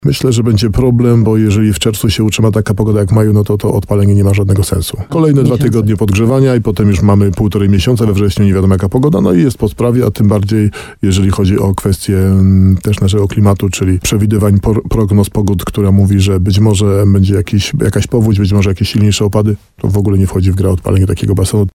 Starosta nowosądecki Tadeusz Zaremba w programie Słowo za Słowo na antenie RDN Nowy Sącz podkreślił, że uruchomienie basenu przez samorząd może okazać się zbyt kosztowne.